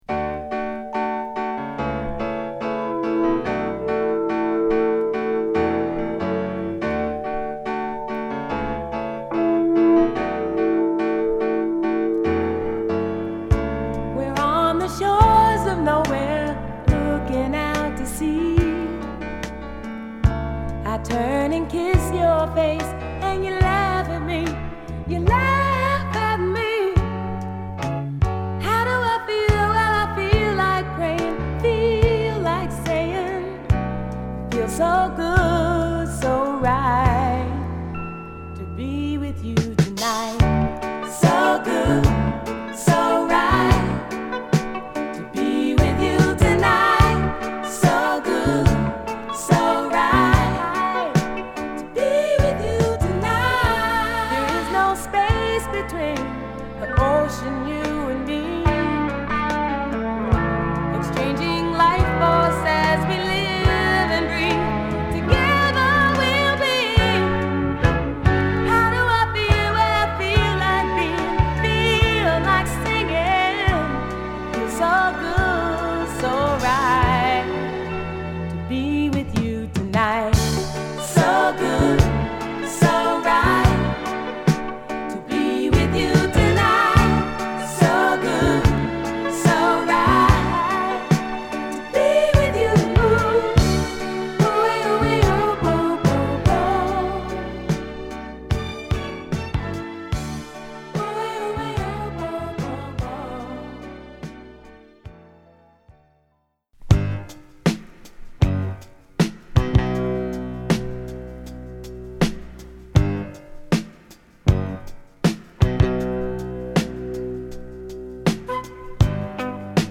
柔らかなピアノが印象的な序盤から、伸びやかなヴォーカルと共に込み上げるサビへの展開も◎な、ほっこりメロウ・チューン！